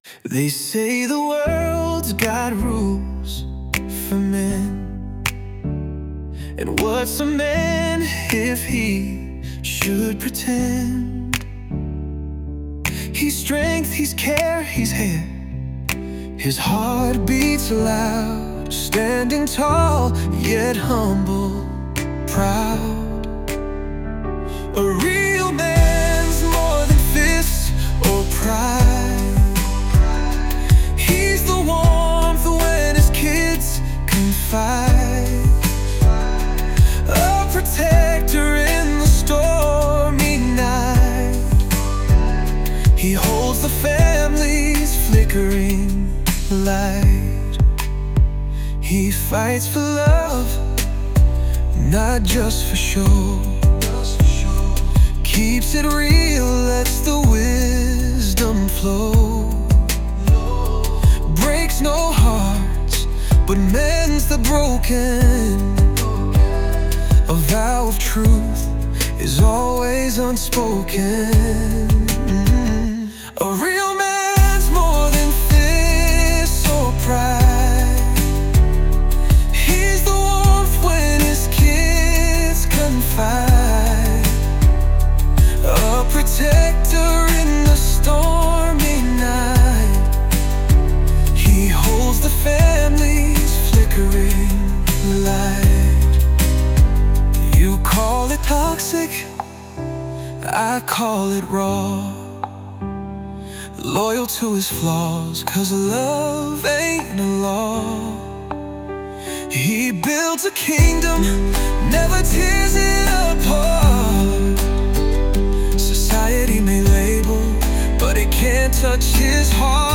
Christian, Gospel